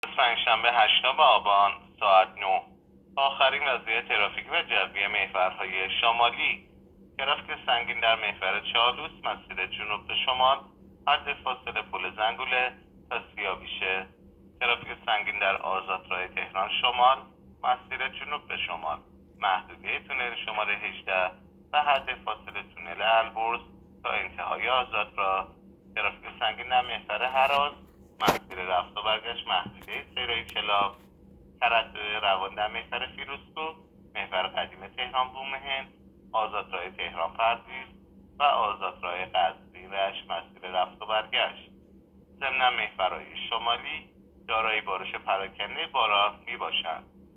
گزارش رادیو اینترنتی از آخرین وضعیت ترافیکی جاده‌ها ساعت ۹ هشتم آبان؛